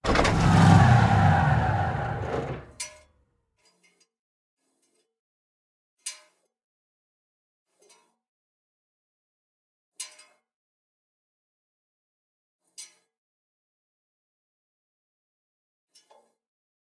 tank-engine-stop-1.ogg